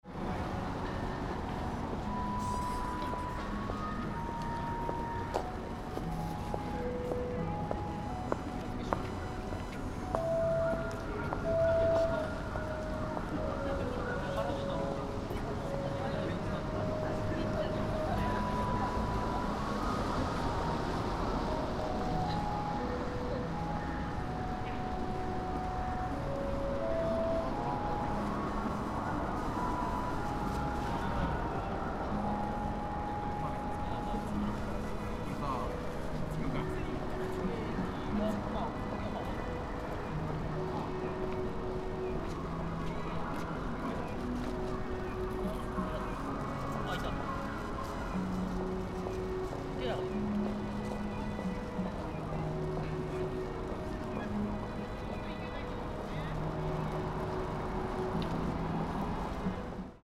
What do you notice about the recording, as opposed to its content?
Fukushima Soundscape: Machi-naka Park ♦ Maybe because I visited the site just before closing time, not so many people were there.